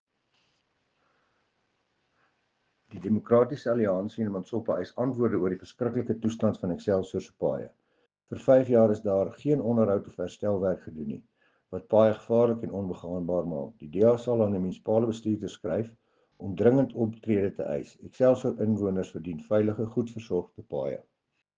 Afrikaans soundbites by Cllr Dewald Hattingh and